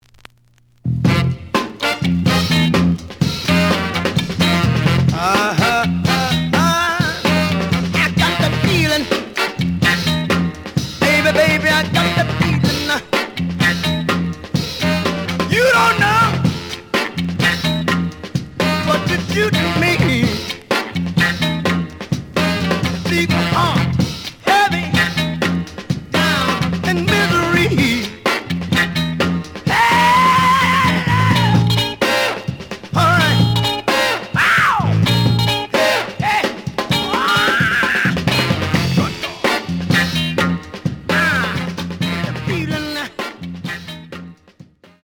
The audio sample is recorded from the actual item.
●Format: 7 inch
●Genre: Funk, 60's Funk